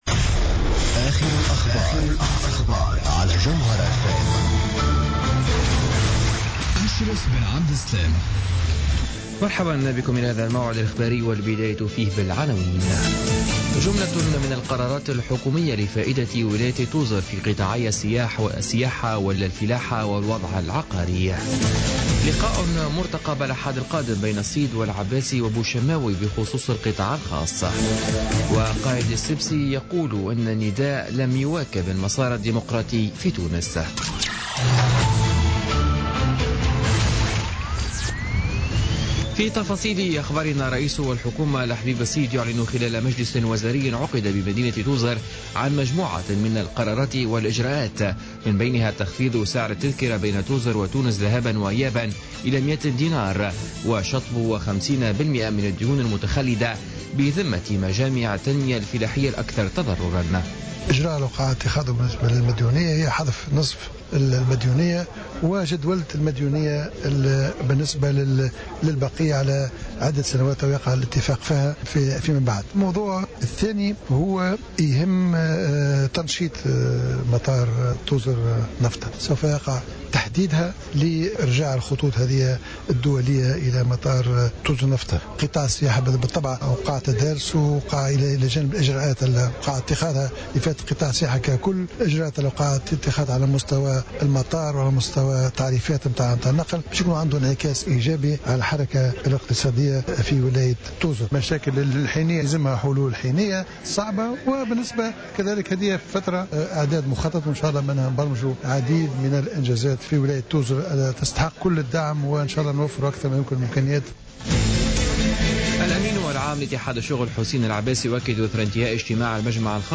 نشرة أخبار منتصف الليل ليوم السبت 26 ديسمبر 2015